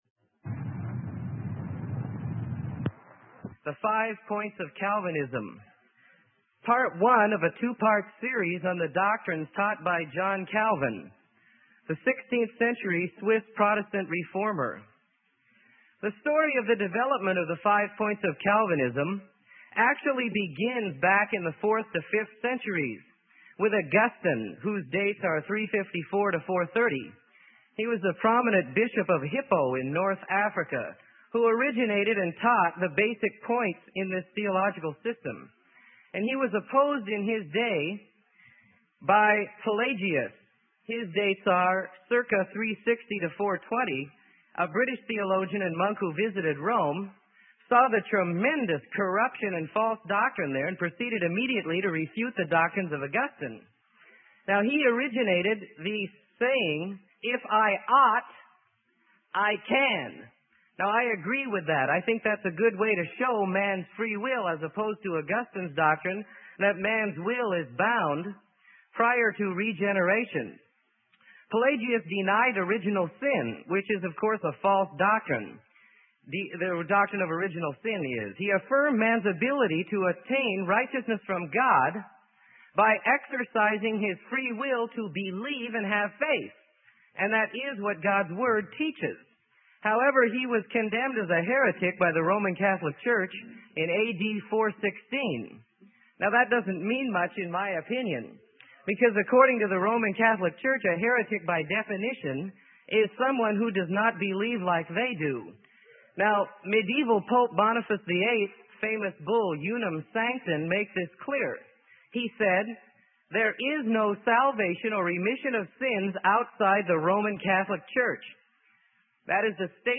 Sermon: The Five Points of Calvinism - Part 1 - Freely Given Online Library